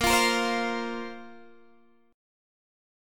A#sus2 chord